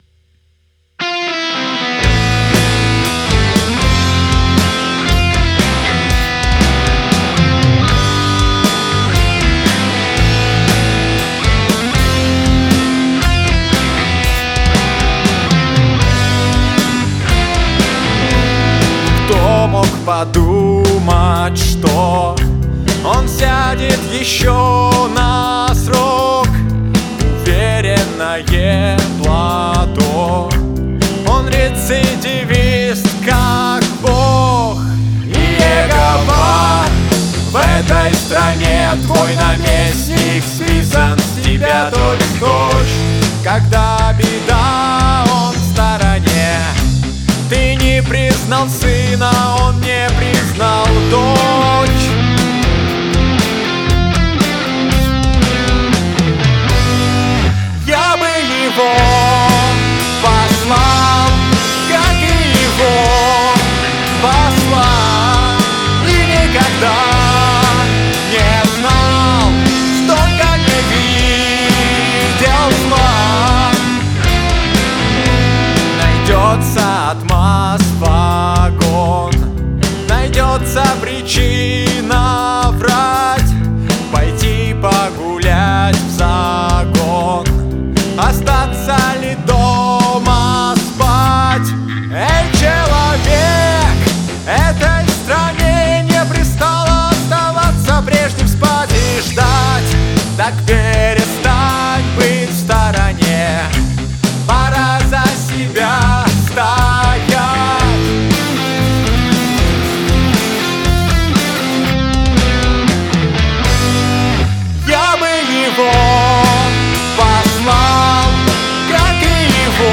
Рок/Альтернатива.
Пишем альбом с другом в полу-домашних условиях и что-то такое выходит